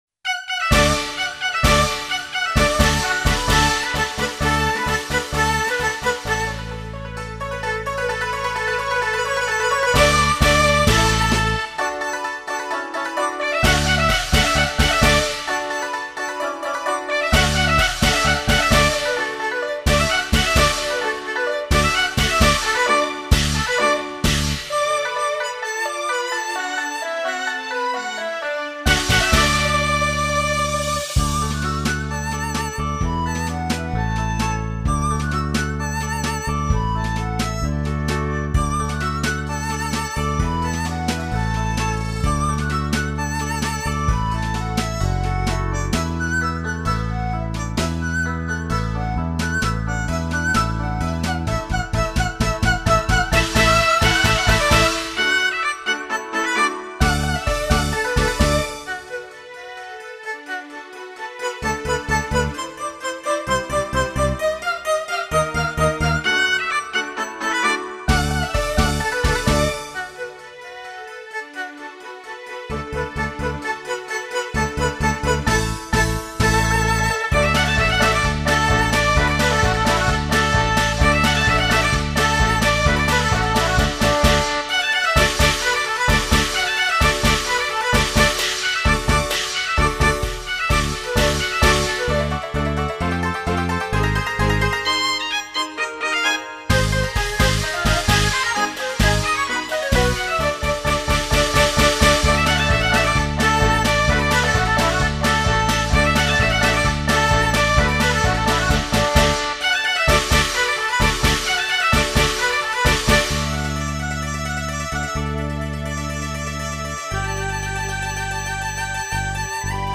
由电子合成器模拟二胡、笛子、扬琴、唢呐、琵琶等乐器
采用充满现代色彩的电子音乐以无比明快轻松的节奏
营造一派欢乐喜庆的音乐气氛